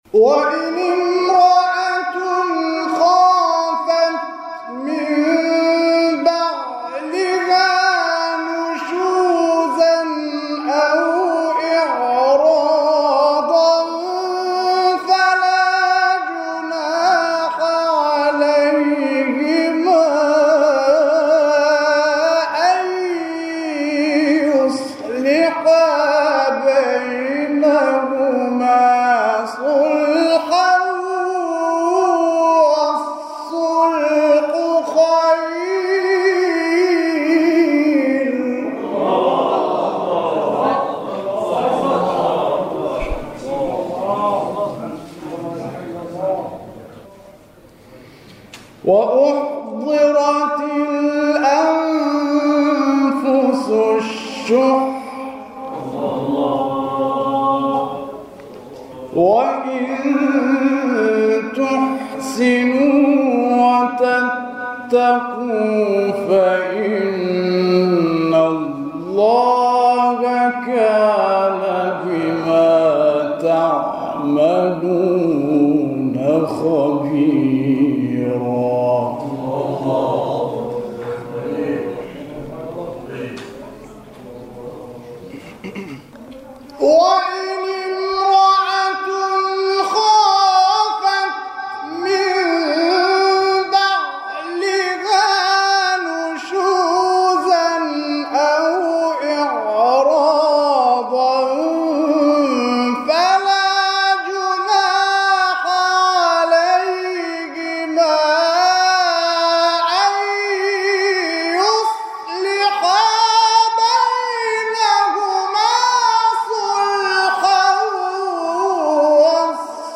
گروه شبکه‌های اجتماعی: فرازهای صوتی از تلاوت قاریان ممتاز و بین المللی کشور که در شبکه‌های اجتماعی منتشر شده است، می‌شنوید.